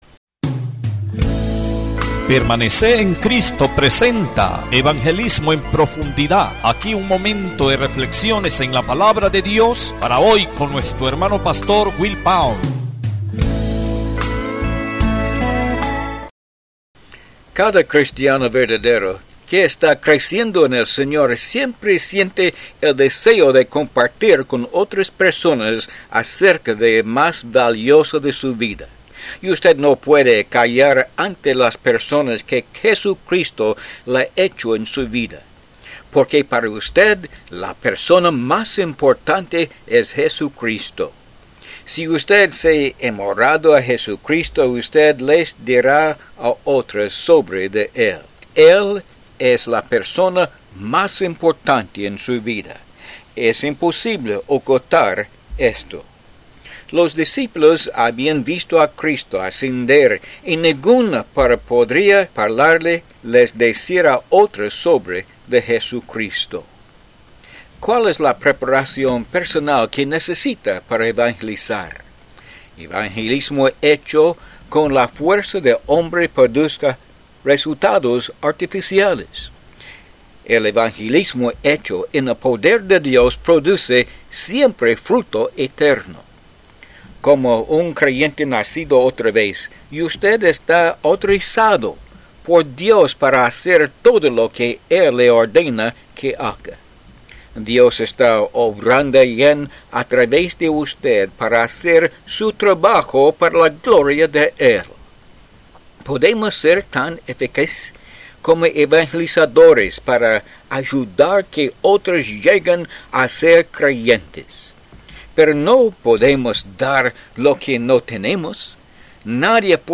Disfrute estos programas de cinco minutos Cristianos de radio.